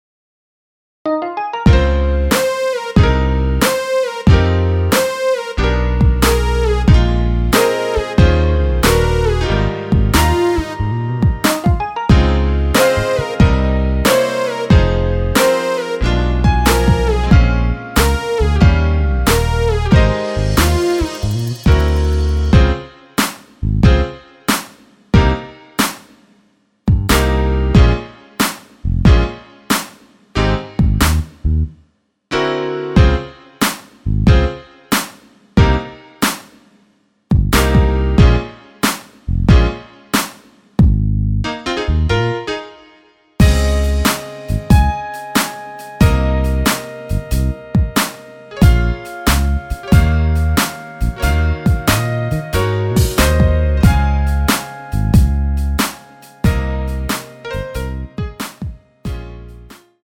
Ab
◈ 곡명 옆 (-1)은 반음 내림, (+1)은 반음 올림 입니다.
앞부분30초, 뒷부분30초씩 편집해서 올려 드리고 있습니다.
중간에 음이 끈어지고 다시 나오는 이유는